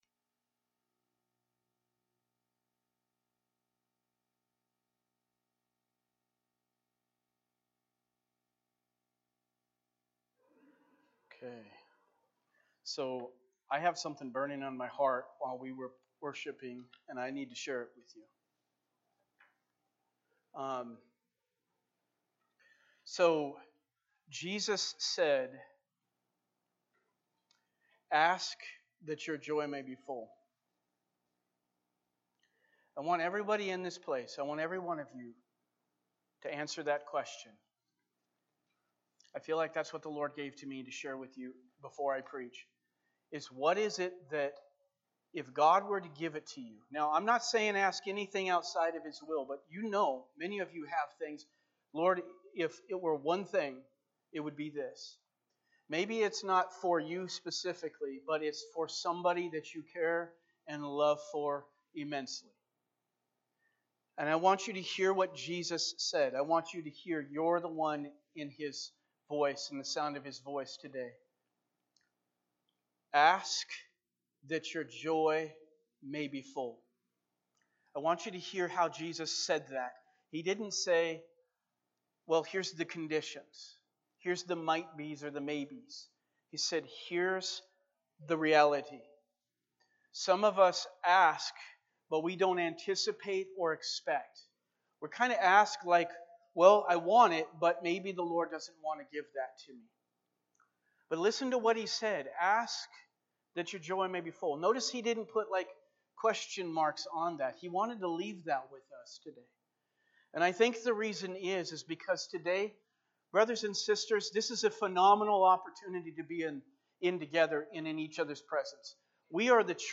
Sermons by Abundant Life Assembly